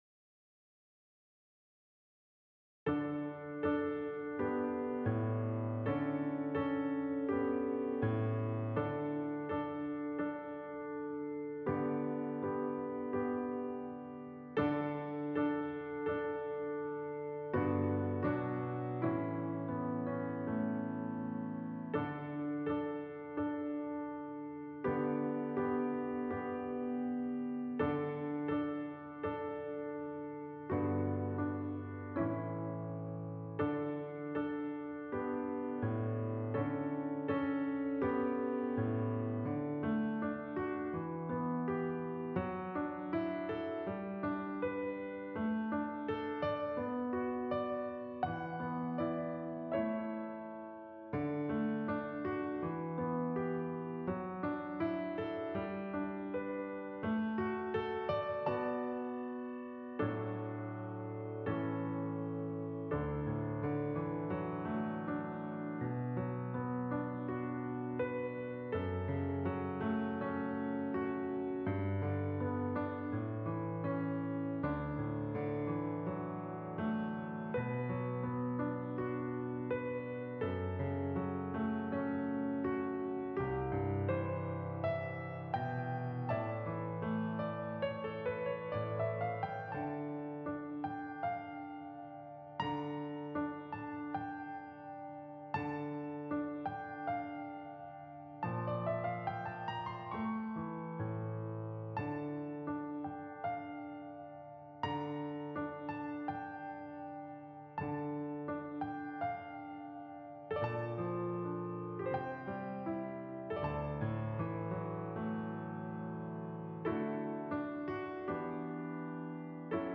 for violin solo with piano accompaniment
Instrumentation: Violin, Piano